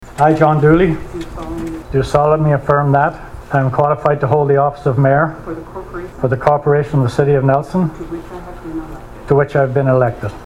Then mayor John Dooley and the new councillors took the oath of office.